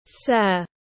Quý ngài tiếng anh gọi là sir, phiên âm tiếng anh đọc là /sɜːr/.
Sir /sɜːr/